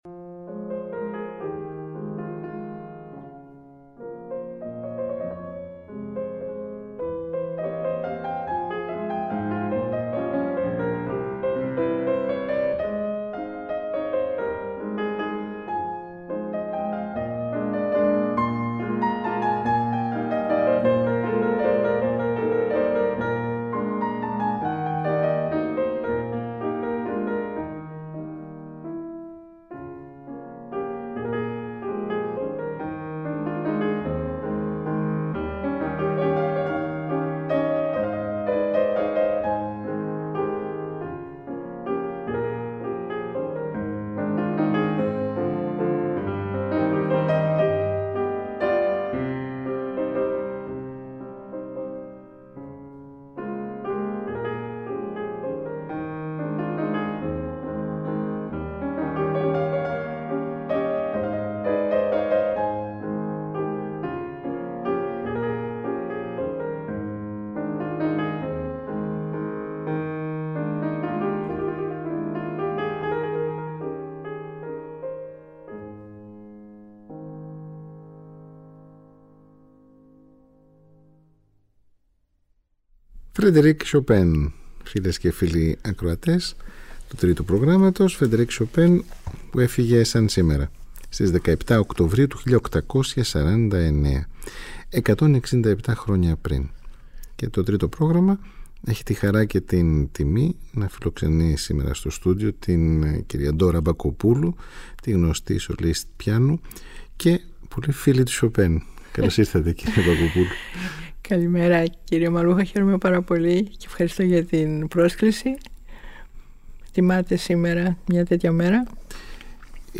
Η Ντόρα Μπακοπούλου στο Τρίτο Πρόγραμμα 90,9, μιλάει για τον Frederic Chopin. Ειδική εκδοχή για ανάρτηση στο διαδίκτυο με ημιτελή τα μουσικά έργα – νέες ηχογραφήσεις ( Ιούλιος 2016 ) της Ντόρας Μπακοπούλου με έργα του Πολωνού συνθέτη.
Impromptus, Βαλς, Νυχτερινά, Πολωνέζες, Μαζούρκες, Σπουδές με την Ντόρα Μπακοπούλου στο πιάνο και στο μικρόφωνο.
Μαζί της στη δίωρη συνομιλία